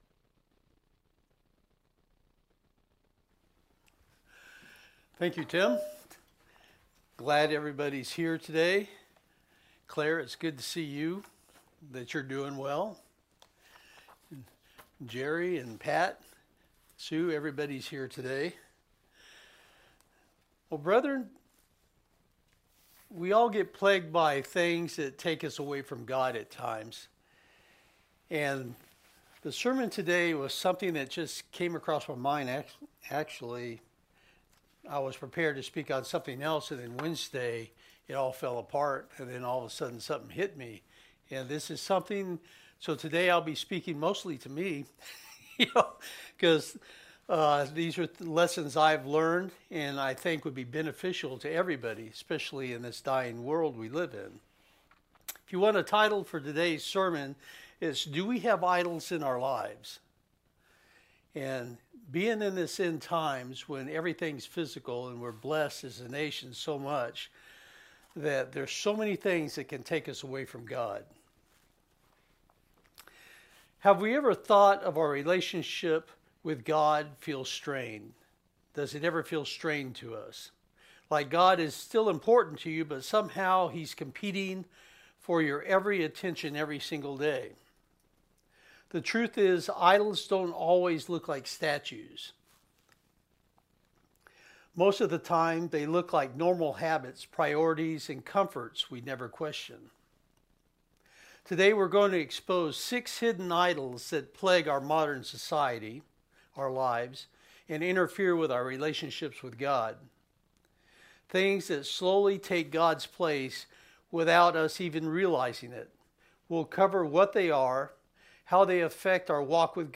New Sermon | PacificCoG
From Location: "Kennewick, WA"